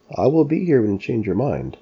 Average Male